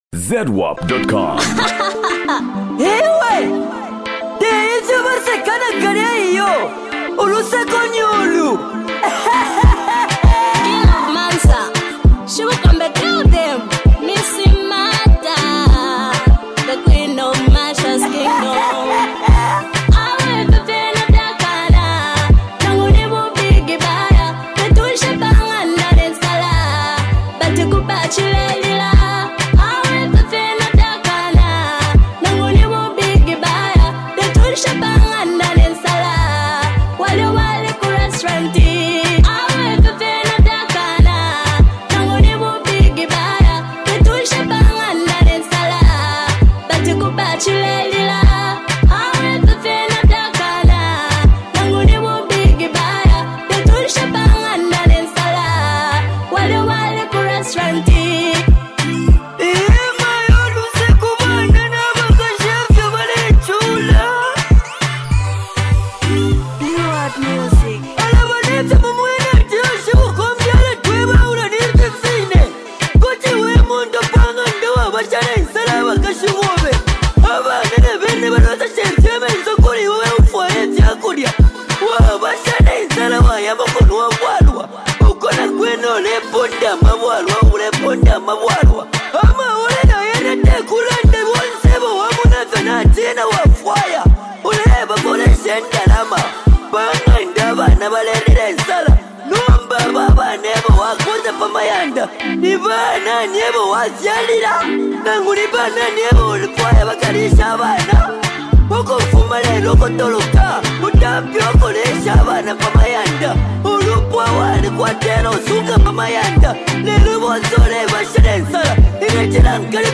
Genre: Afro-beats, Zambia Songs